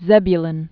(zĕbyə-lən)